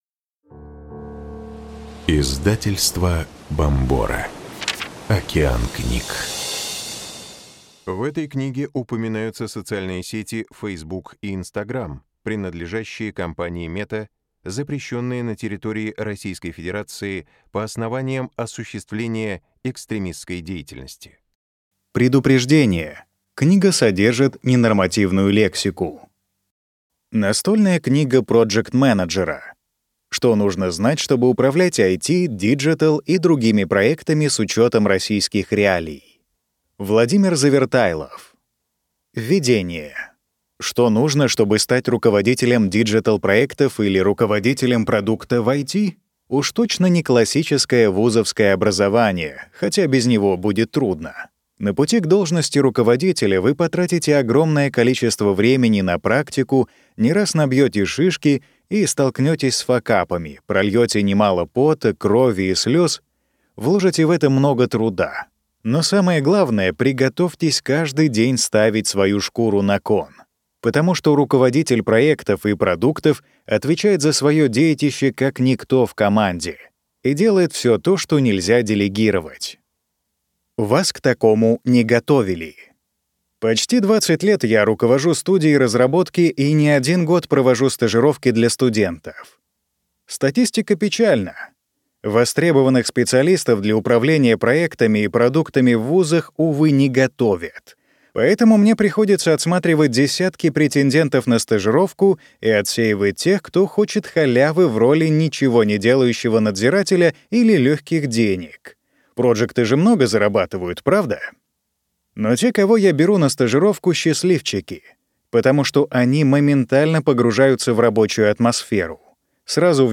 Аудиокнига Настольная книга project-менеджера. Что нужно знать, чтобы управлять IT, digital и другими проектами с учетом российских реалий | Библиотека аудиокниг